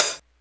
hihat03.wav